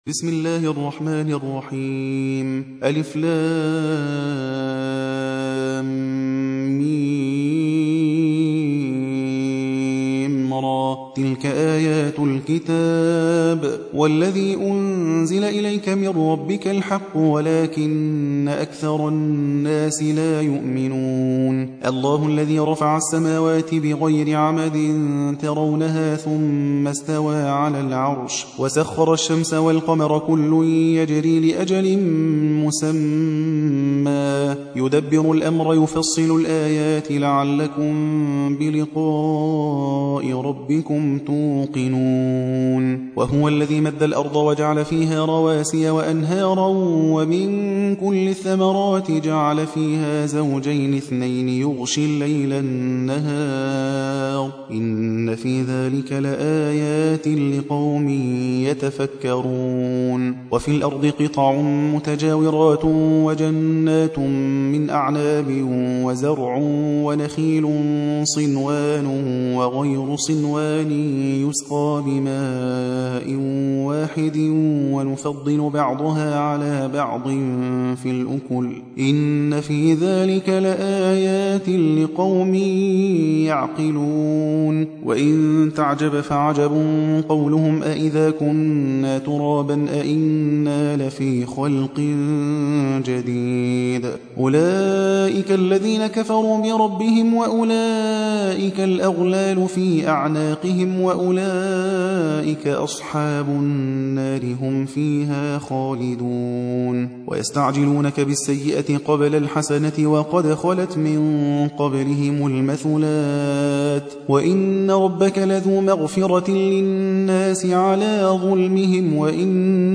13. سورة الرعد / القارئ